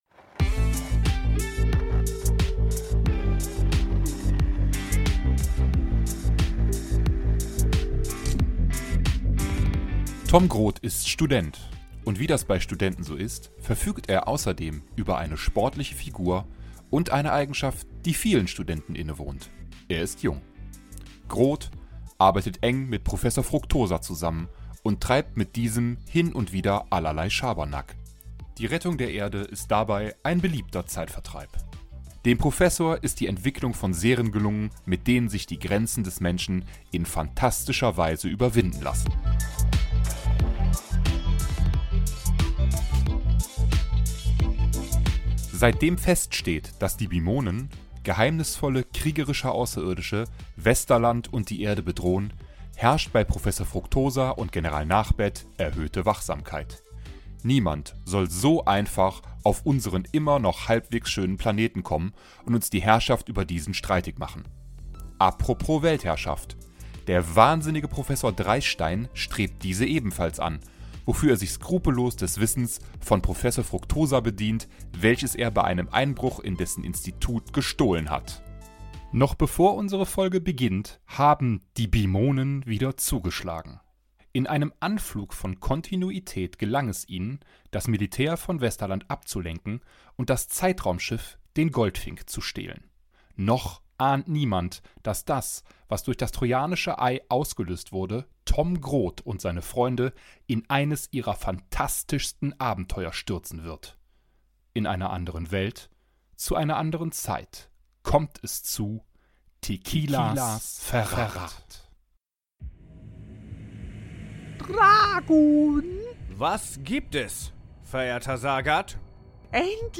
Tequilas Verrat (Tom Grot II) - Das zweite Fanhörspiel - Die Silbervögel (#59) ~ Die Silbervögel - Der Jan Tenner Podcast